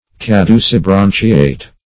Search Result for " caducibranchiate" : The Collaborative International Dictionary of English v.0.48: Caducibranchiate \Ca*du`ci*bran"chi*ate\, a. [L. caducus falling (fr. cadere to fall) + E. branchiate.]